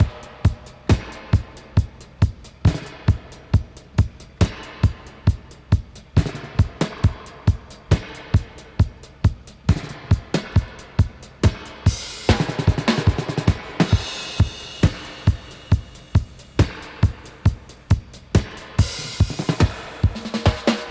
136-DUB-03.wav